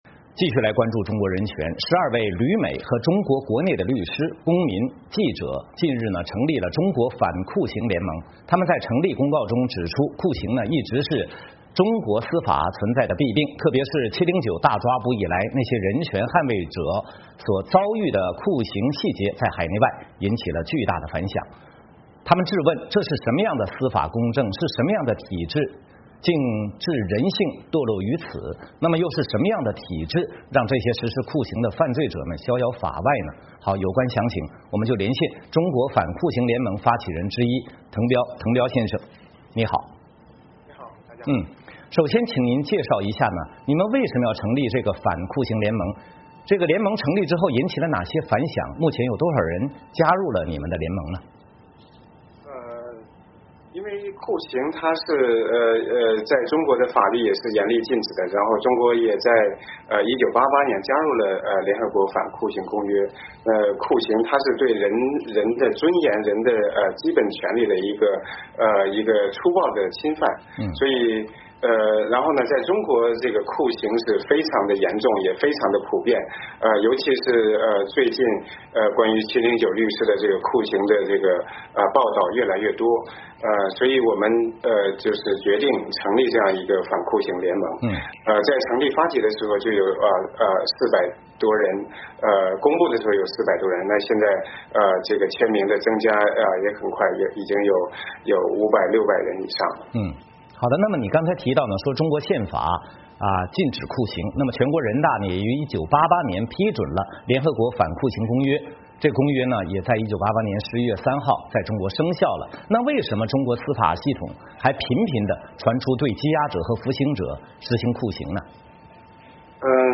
有关详情，我们连线中国反酷刑联盟发起人之一滕彪。